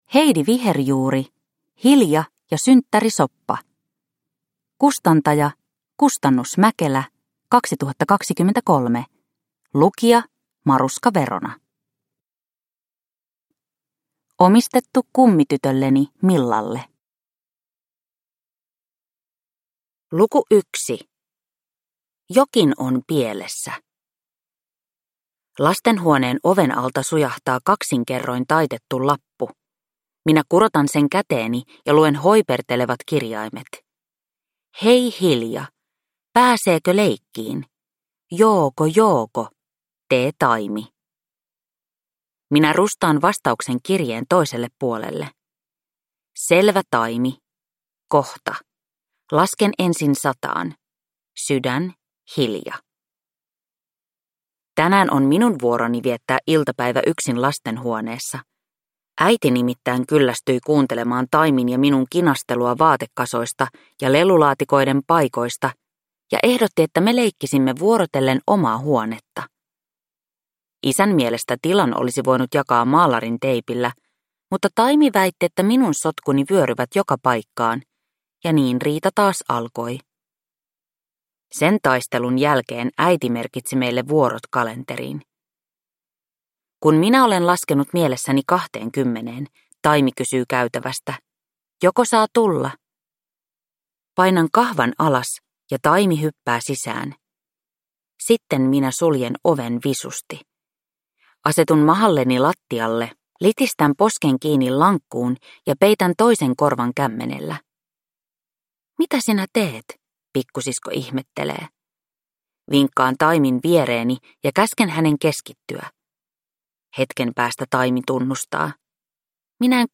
Hilja ja synttärisoppa – Ljudbok